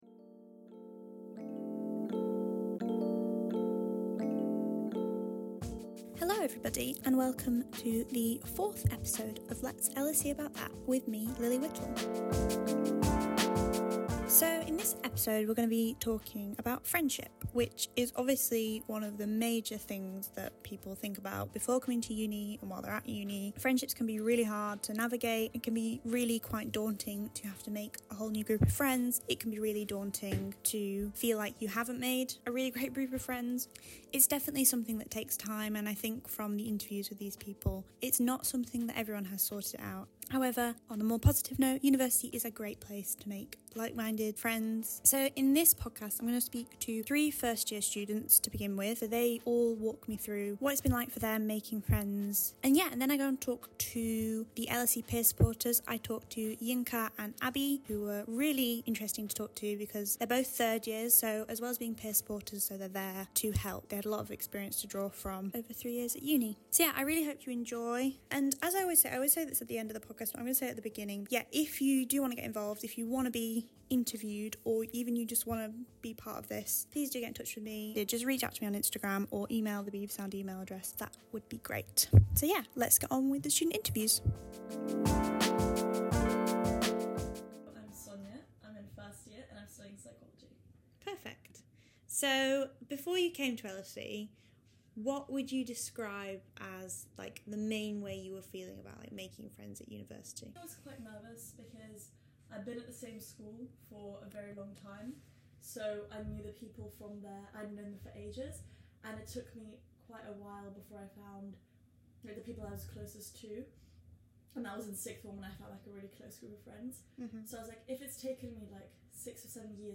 Every episode I interview a handful of students and talk to them about a specific student issue, discussing what they know and what they want to know.
Making friends at university is never easy, but you’re not alone! In this episode I talk to three freshers about their experience settling in and making friends at uni.